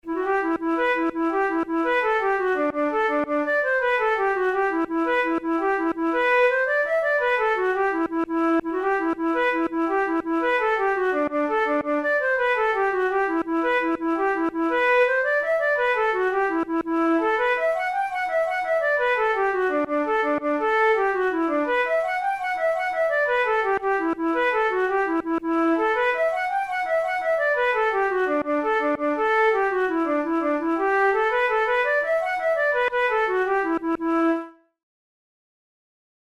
InstrumentationFlute solo
KeyE minor
Time signature6/8
Tempo112 BPM
Celtic Music, Jigs, Traditional/Folk
Traditional Irish jig